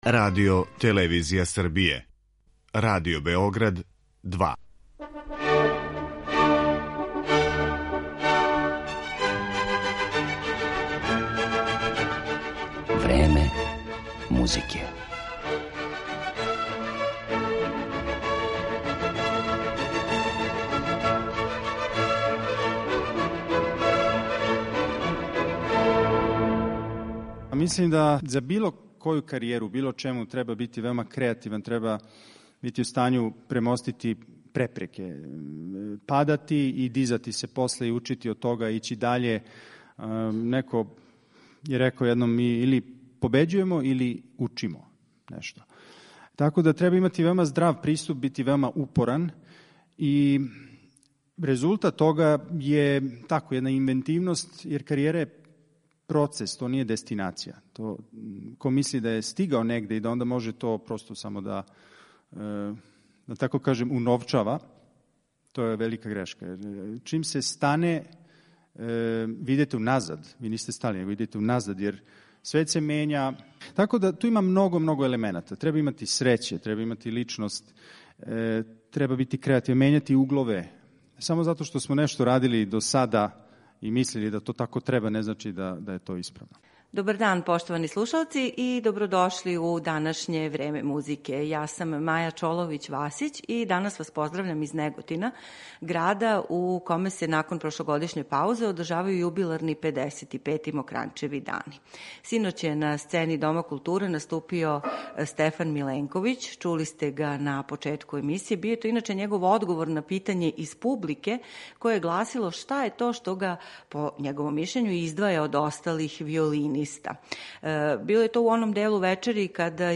Тим поводом, данашње Време музике емитујемо из овог града, а о фестивалу ‒ до сада одржаним концертима, представама, промоцијама и радионицама ‒ говоре: